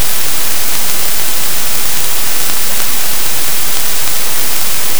In engineering, we call this Barkhausen noise. It’s the sound magnetic domains make as they snap into alignment. It’s not smooth. It’s jagged. It’s loud.
• The Saturation: The jagged “teeth” of the noise. In audio terms, that’s saturation. You’re pushing the signal so hard the math breaks.
• The Hiss: The underlying mycelial vibrations—a kind of biological static.